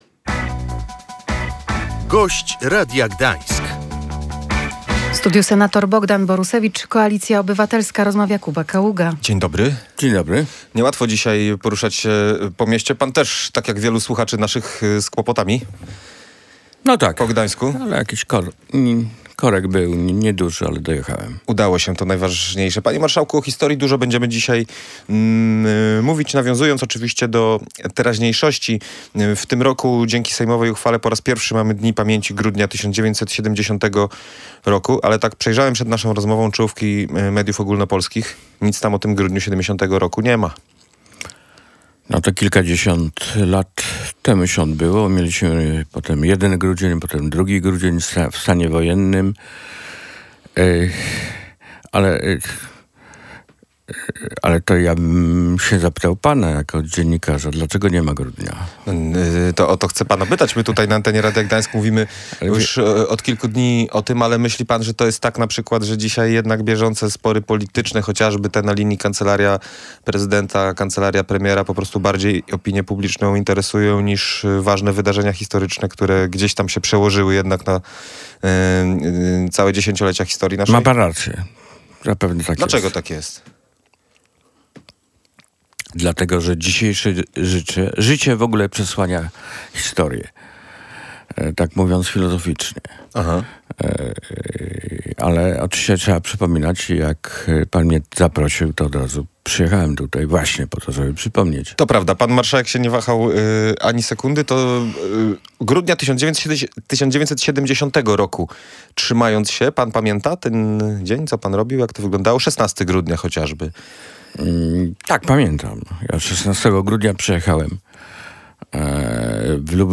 Jako studenci dostaliśmy wolne, wróciłem na Wybrzeże i przeżyłem szok – tak grudzień 1970 roku wspomina senator Koalicji Obywatelskiej Bogdan Borusewicz.